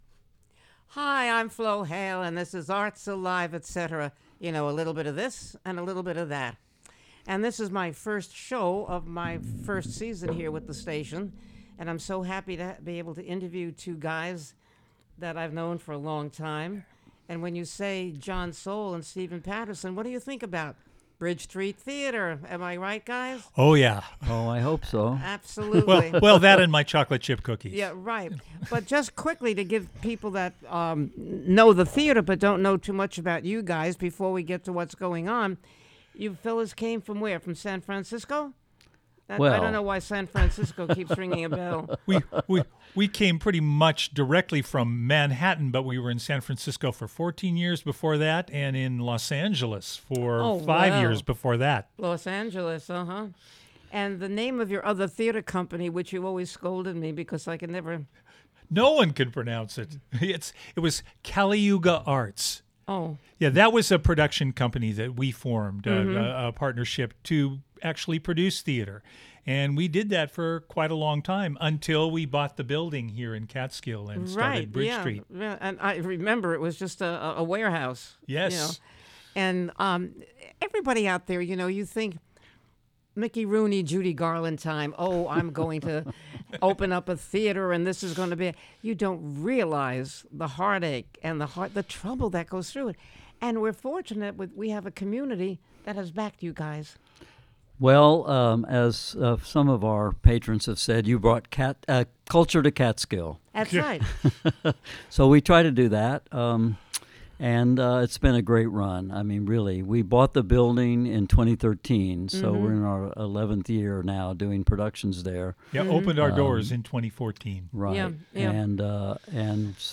With a wicked wit and a true heart she banters with fellow singers and thespians, local luminaries and mover/shakers and knows how to get them to reveal what makes them tick.